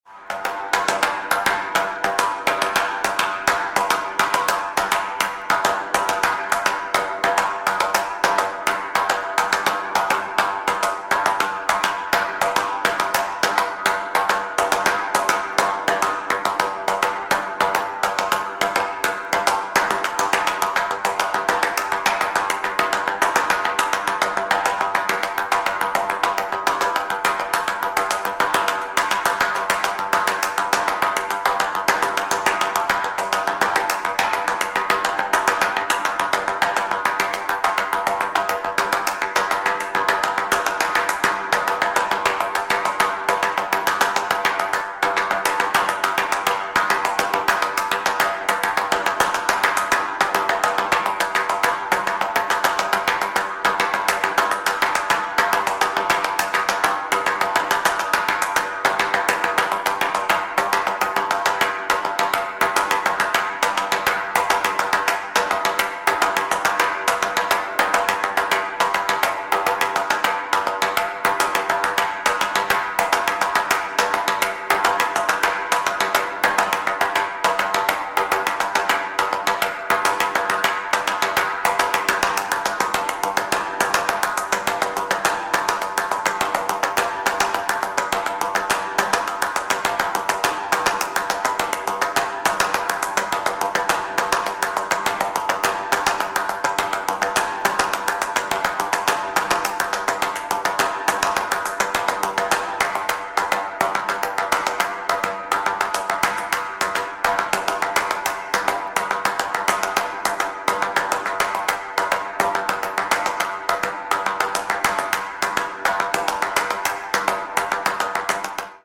minimalisms
flutist